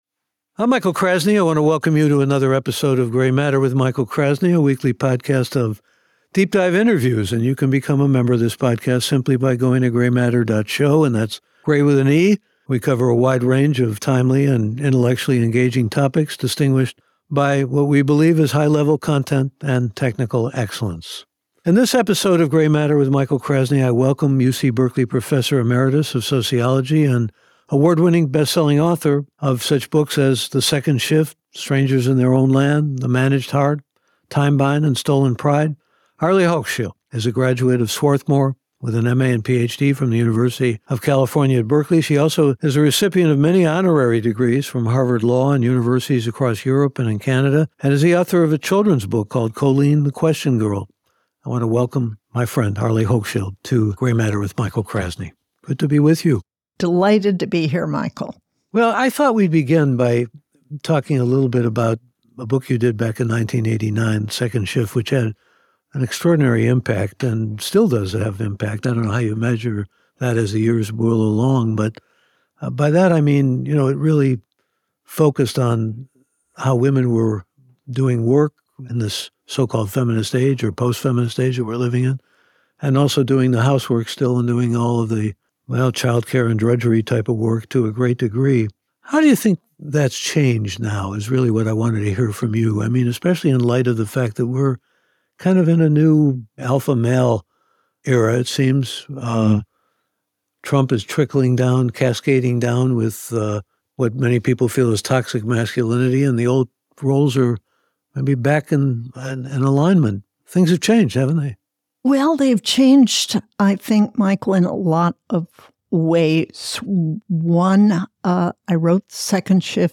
Award winning broadcaster, author, and professor Michael Krasny shares in-depth interviews with leading newsmakers, scholars, authors and intellectuals.